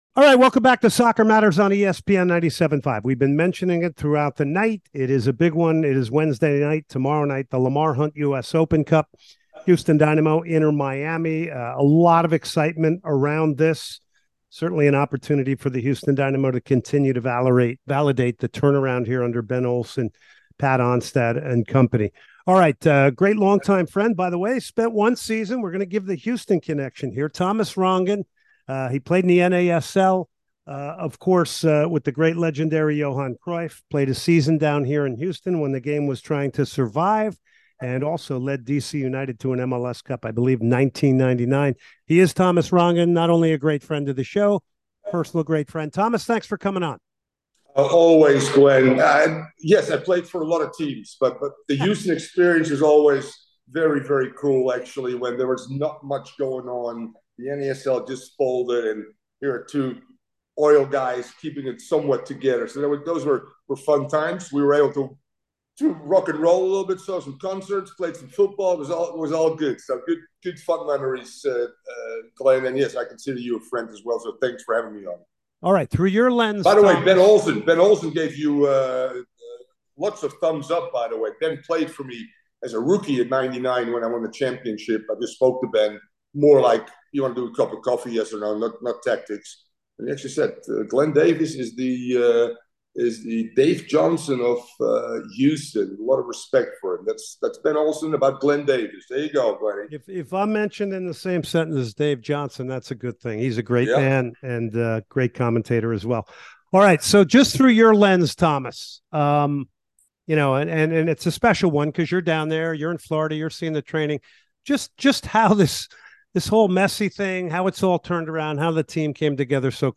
He joins for an interview to talk on the US Open cup final between Houston and Miami. A lot of insight from Miami point of view; news, injuries, & much more.